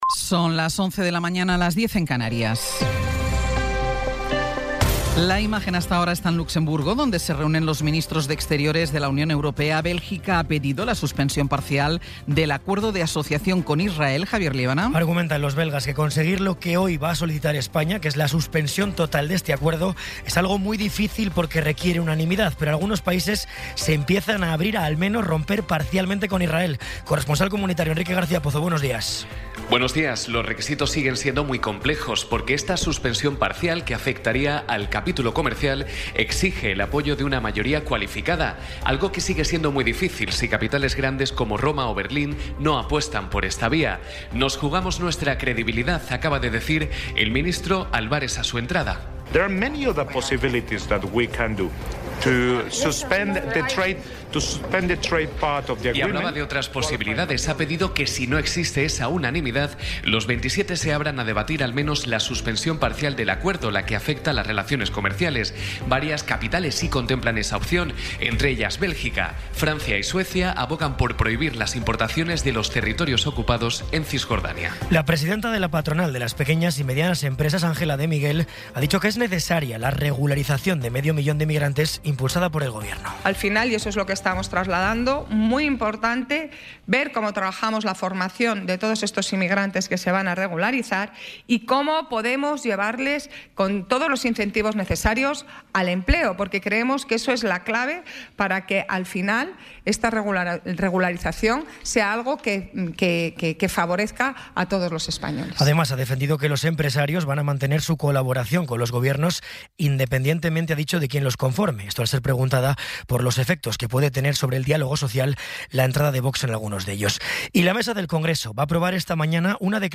Resumen informativo con las noticias más destacadas del 21 de abril de 2026 a las once de la mañana.